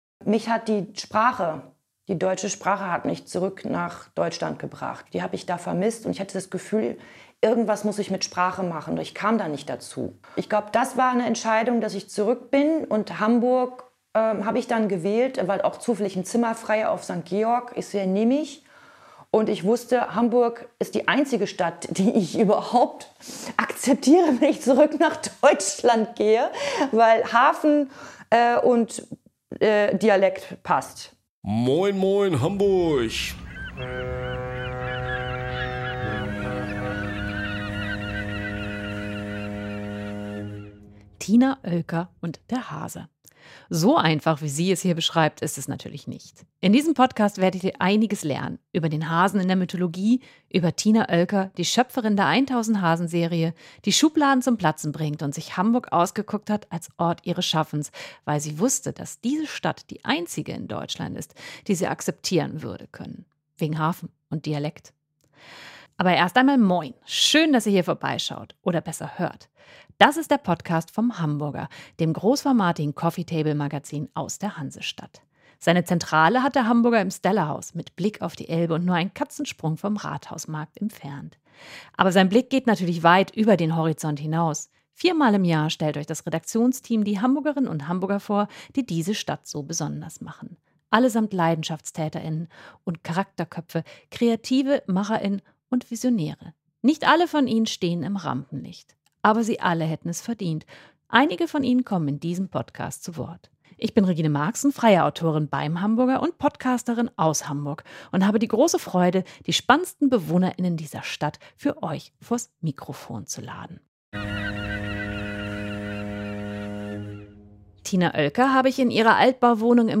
Wir treffen uns in ihrer Altbauwohnung im Karolinenviertel.